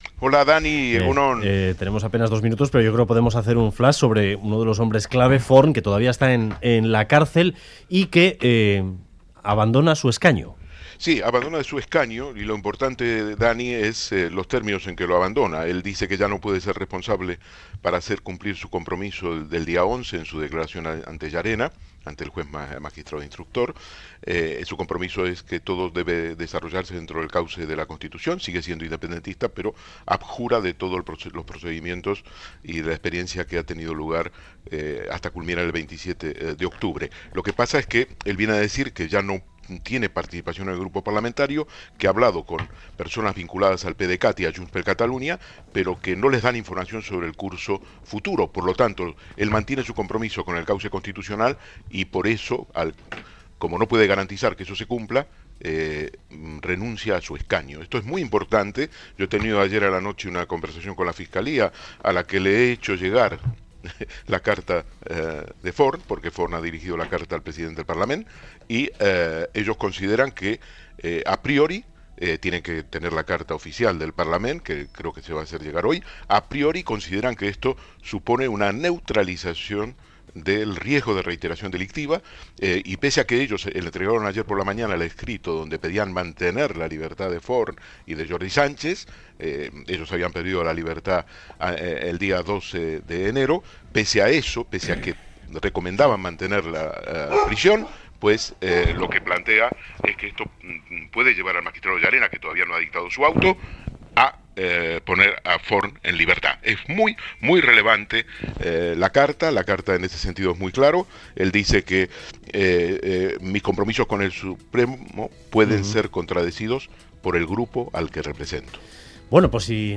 Radio Euskadi BOULEVARD Ernesto Ekaizer: 'Renunciando a ser diputado, Forn quedaría en libertad' Última actualización: 24/01/2018 10:16 (UTC+1) El periodista anticipa en 'Boulevard' de Radio Euskadi que el ex conseller de Interior quedaría en libertad en cuanto la fiscalía y el juez tengan su carta de renuncia a su acta de diputado.